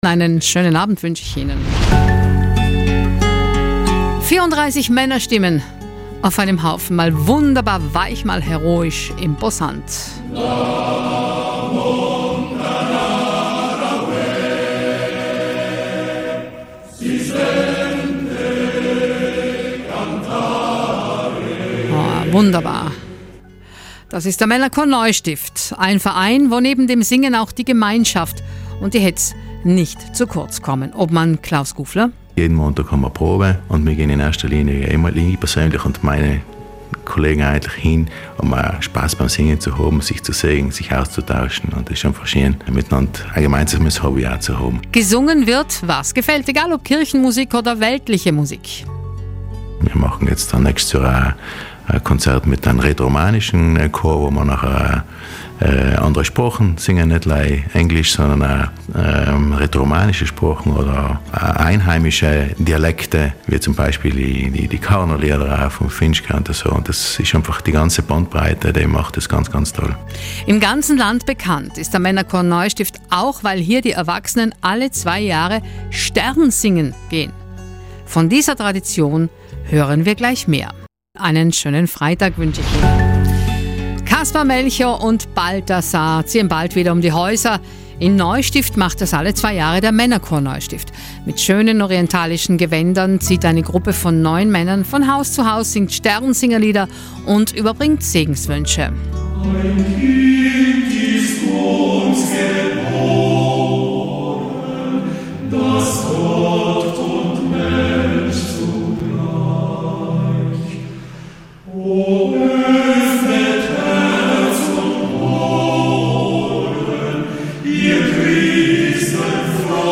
Der Männerchor Neustift, ein traditionsreicher Verein, pflegt seit 100 Jahren die seltene Tradition erwachsener Sternsinger. In schönen orientalischen Gewändern ziehen die neun Männer des Chors alle zwei Jahre von Haus zu Haus, singen Sternsingerlieder und überbringen Segenswünsche. Der Chor feiert 2024 das 100-jährige Jubiläum des Sternsingens in Neustift und lädt interessierte neue Mitglieder herzlich ein.